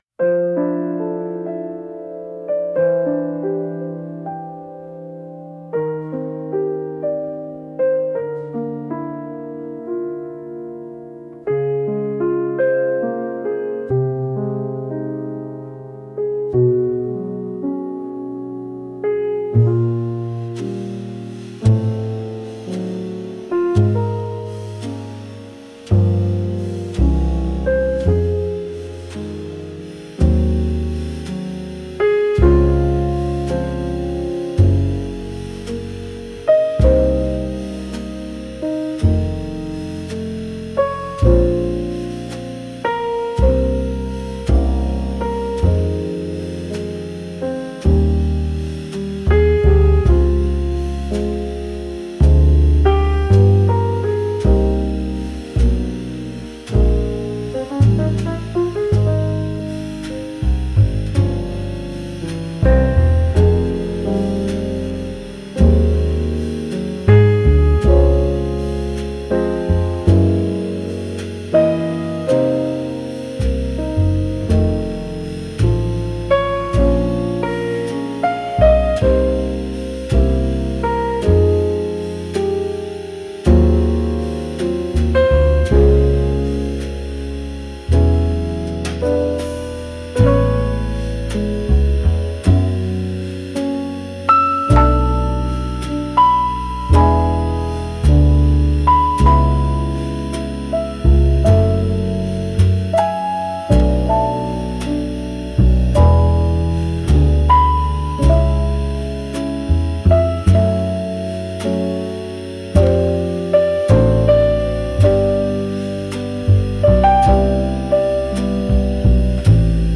大人な雰囲気